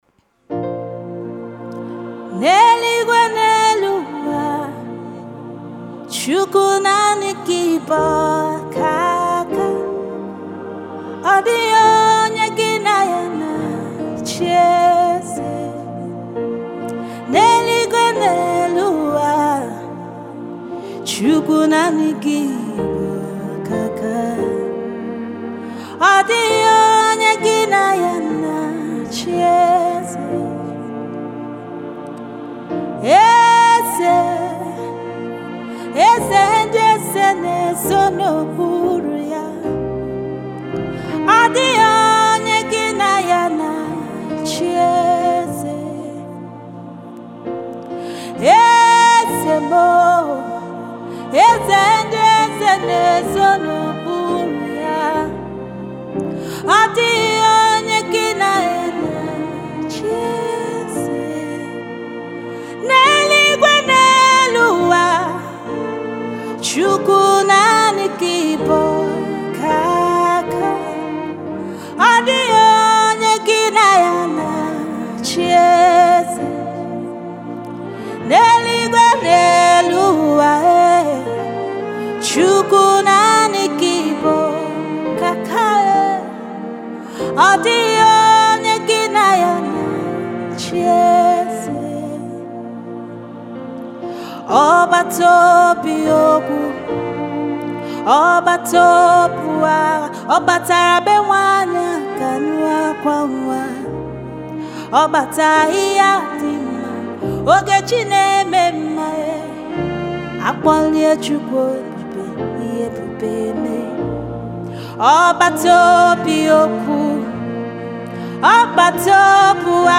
In the vibrant landscape of contemporary gospel music